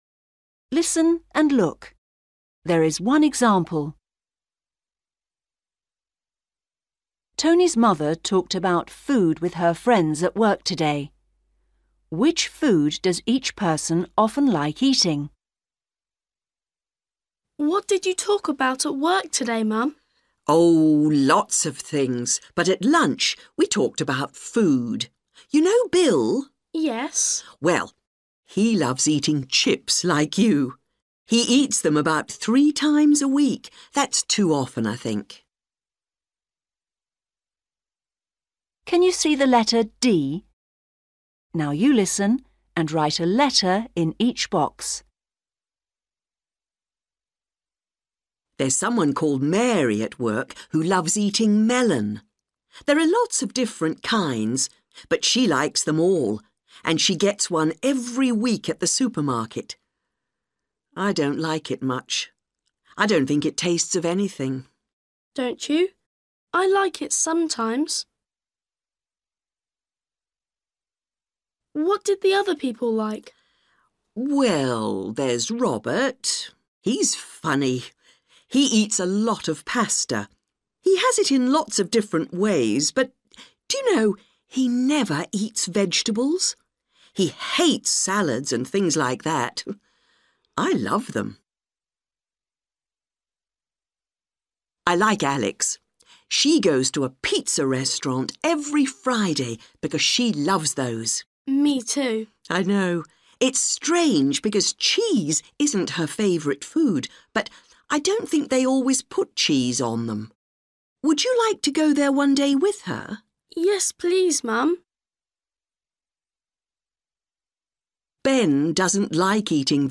Tony’s mother talked about food with her friends at work today.